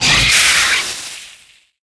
auto_overheat.wav